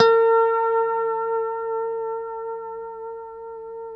B4 PICKHRM1A.wav